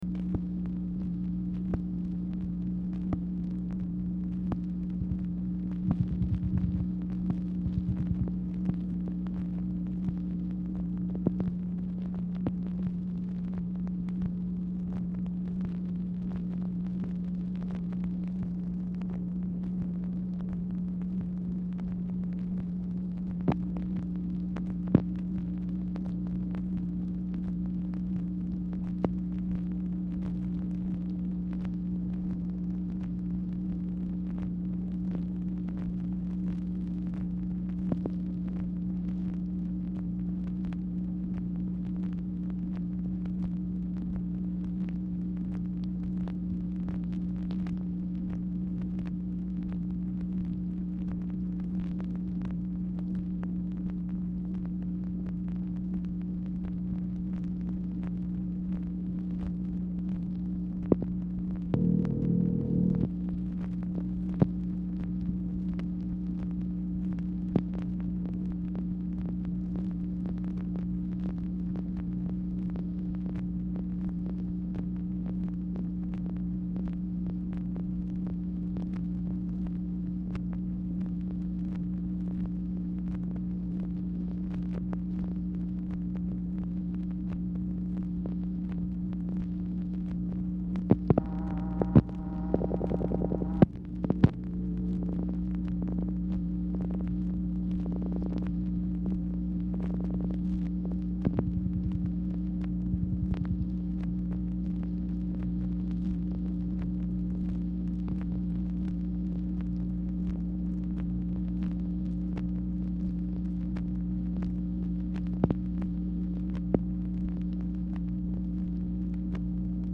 MACHINE NOISE
Format Dictation belt
Specific Item Type Telephone conversation